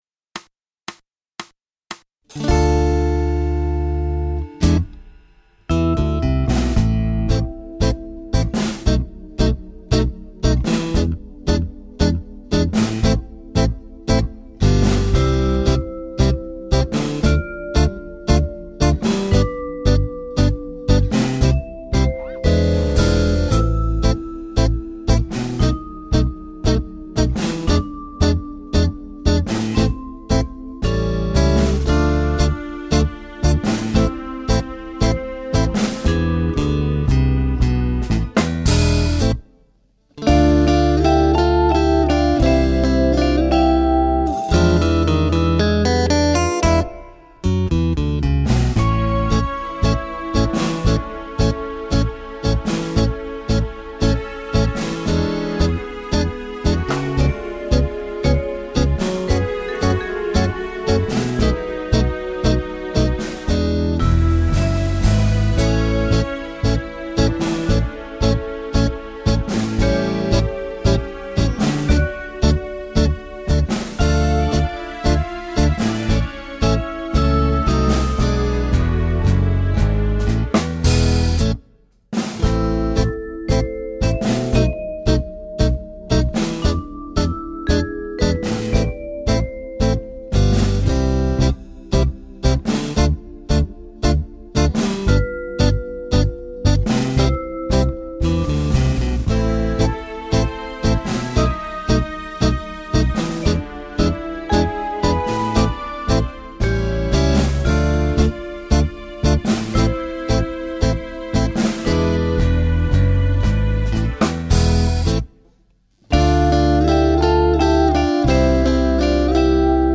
Genere: Tango
Scarica la Base Mp3 (3,04 MB)